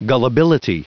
Prononciation du mot gullibility en anglais (fichier audio)
Prononciation du mot : gullibility